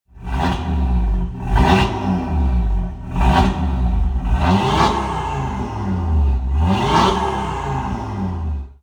• Aluminium Engine Block Model (Non-OPF DAZA Engine that is louder than post 2019 Models)
Listen to the DAZA Symphony!
• RS Sports Exhaust System